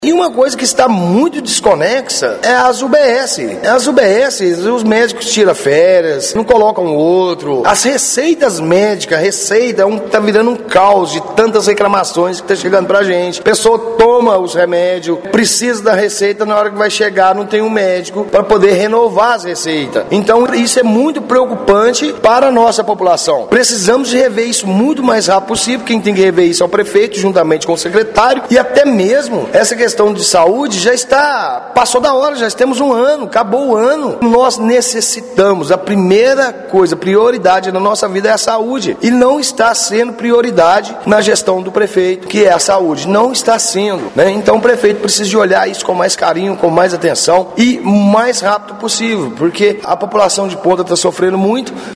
O vereador Cristiano Fernandes, presidente da Comissão de Saúde da Câmara Municipal, voltou a questionar os resultados apontados pela secretaria e afirmou que a população não tem percebido os avanços práticos no atendimento.